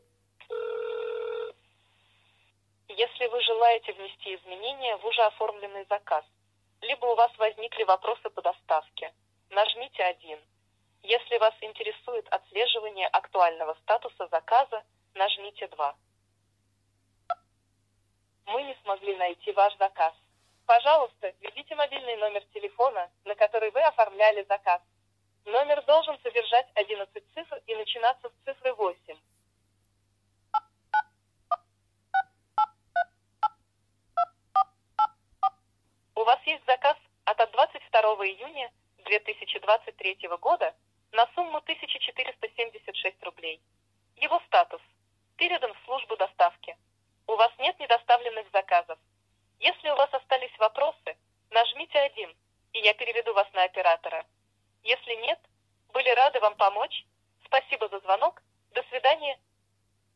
IVR
• Если на этот номер телефона в последние 30 дней был оформлен заказ, то робот автоматически сообщает звонящему его статус, срок хранения, планируемую дату доставки и т.д.
• Если номер не определяется или на определившийся номер нет привязанных заказов, то робот просит ввести номер телефона вручную.